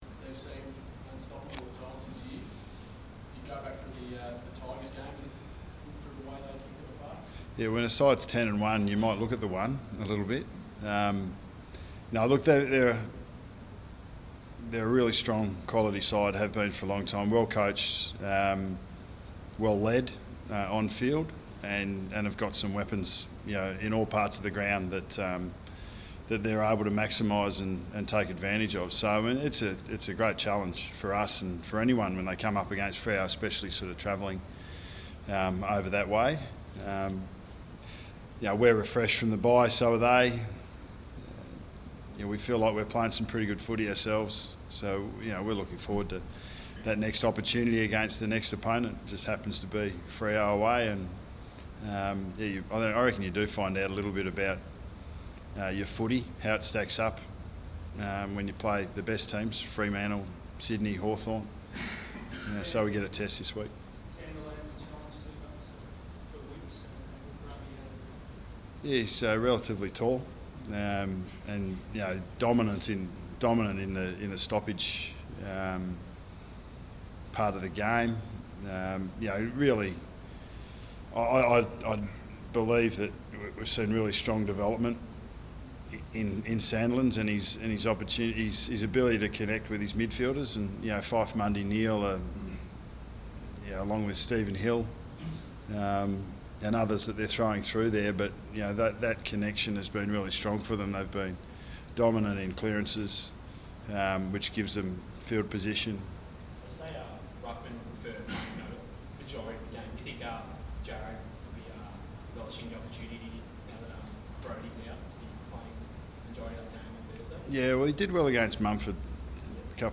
Press Conference: Nathan Buckley
Listen to Nathan Buckley's press conference at the Westpac Centre on Tuesday 23 June 2015.